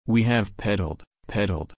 /ˈpɛdəl/